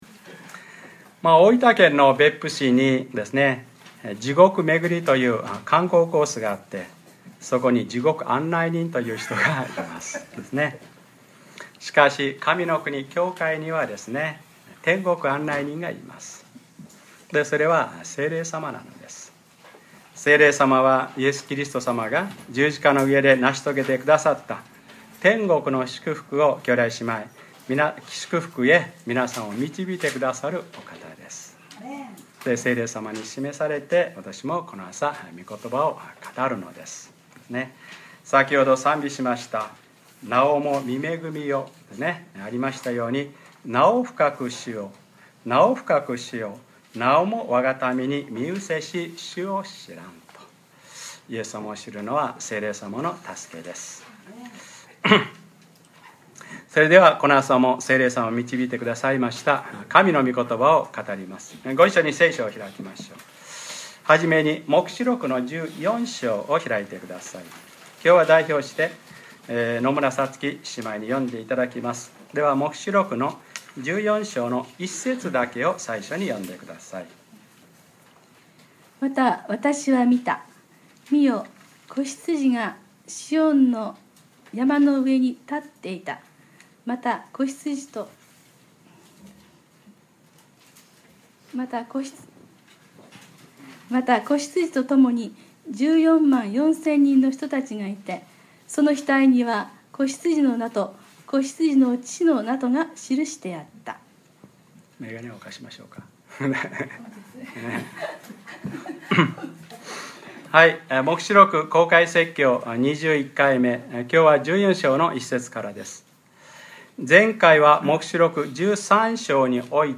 2013年7月28日(日）礼拝説教 『黙示録ｰ２１ 聖徒たちの忍耐はここにある』